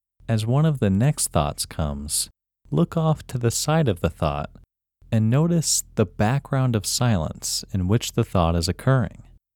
WHOLENESS English Male 3
WHOLENESS-English-Male-3.mp3